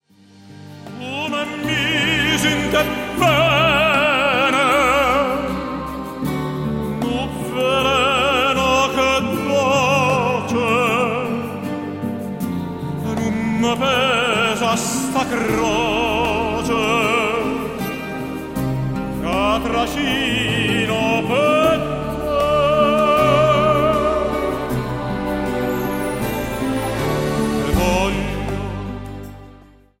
key: F-major